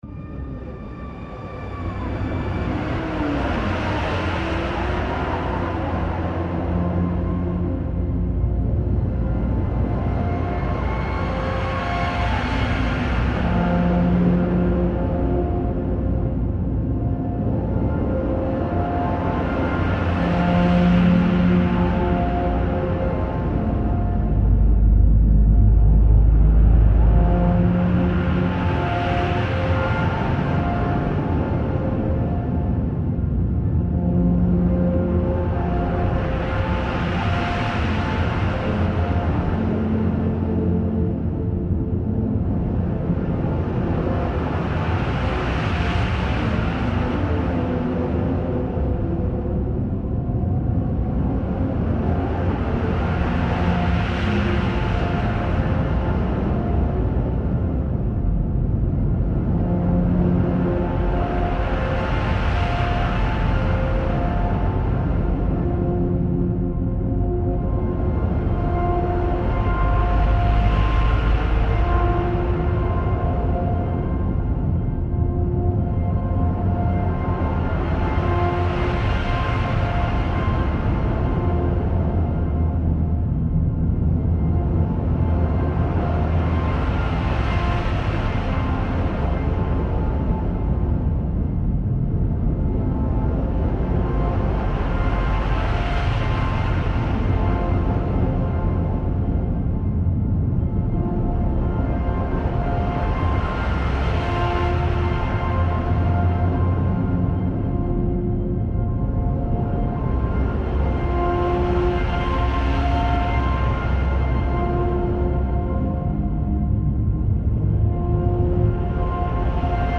concert ending reimagined